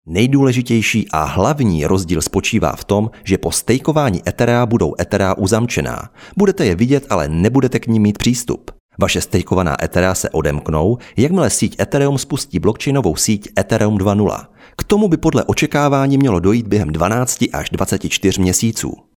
Umím: Voiceover
Mužský voiceover do Vašich videí